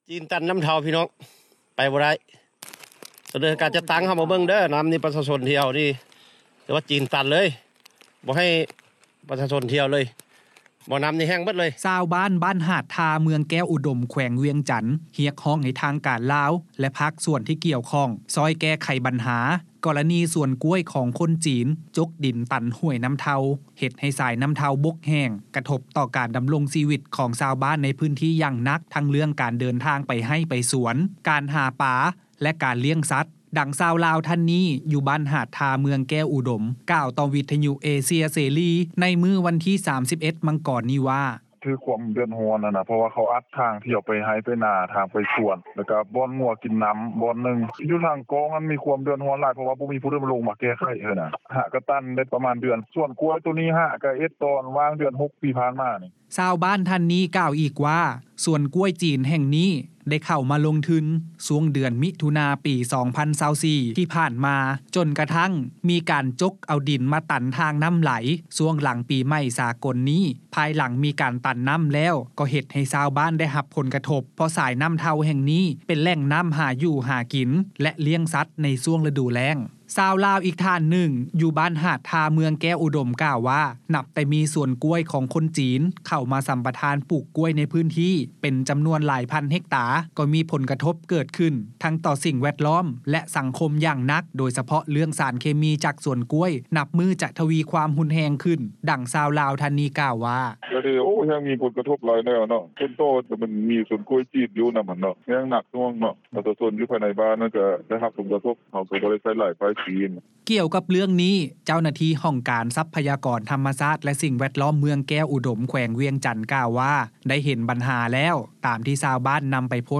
ດັ່ງຊາວລາວ ທ່ານນີ້ ຢູ່ບ້ານຫາດທາ ເມືອງແກ້ວອຸດົມ ກ່າວຕໍ່ວິທຍຸເອເຊັຽເສຣີ ໃນມື້ວັນທີ 31 ມັງກອນ ນີ້ວ່າ: